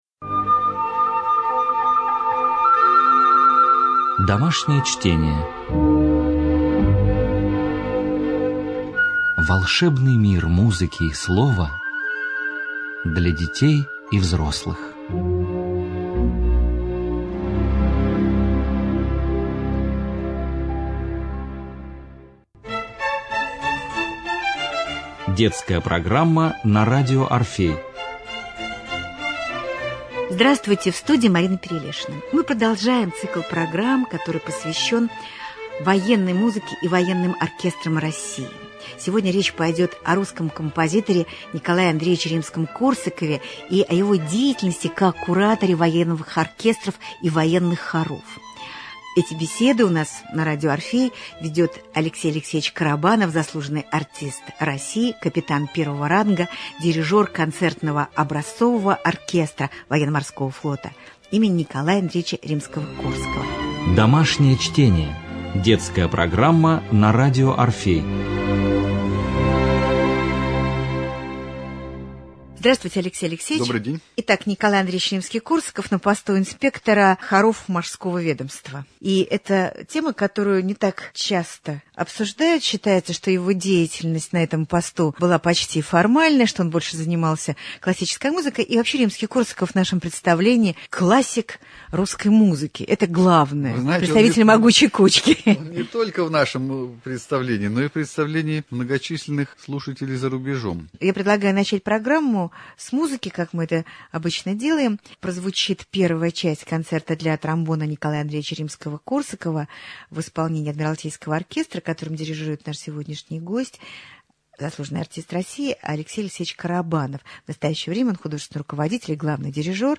Цикл бесед о патриотической и военной музыке